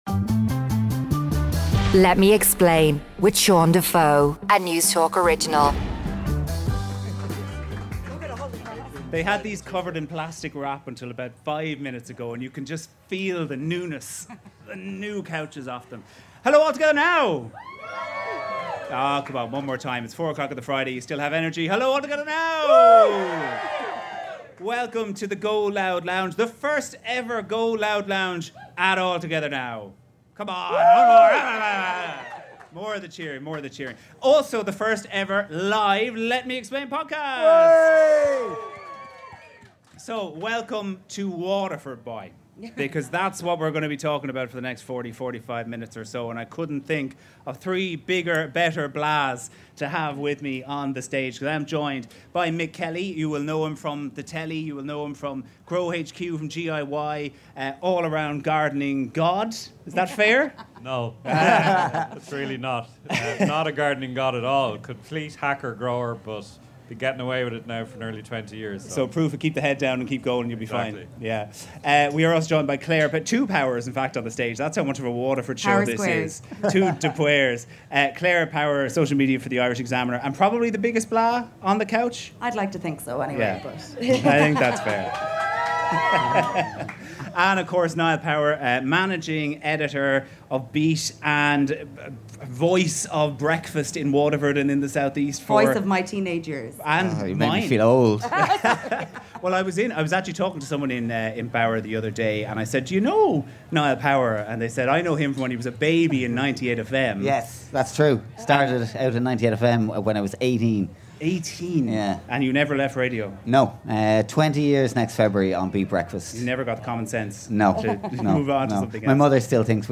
Coming to you live from All Together Now it's the first LIVE Let Me Explain podcast.